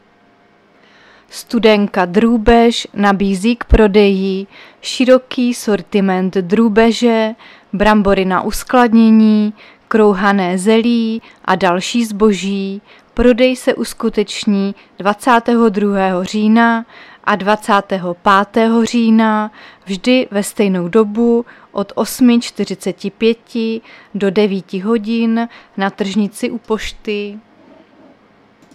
Záznam hlášení místního rozhlasu 21.10.2024
Zařazení: Rozhlas